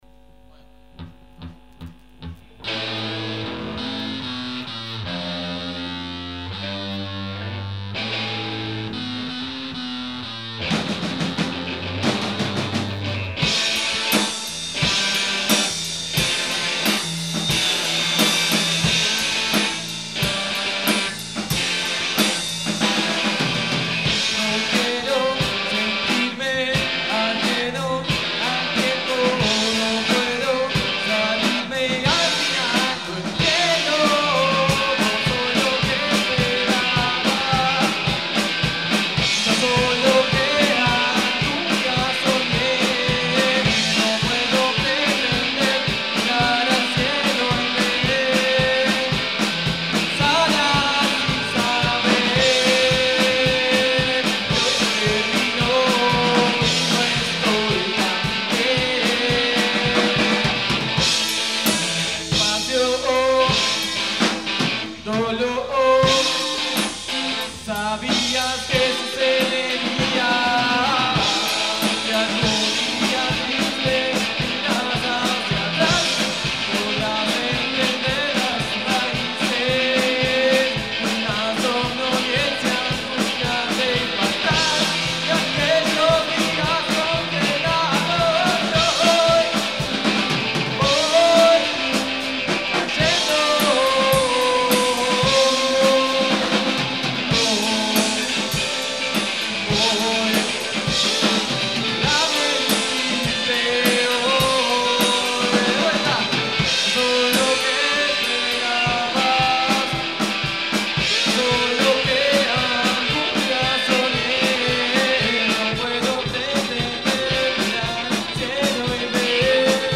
Rock